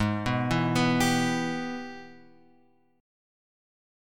G# Minor Major 7th Sharp 5th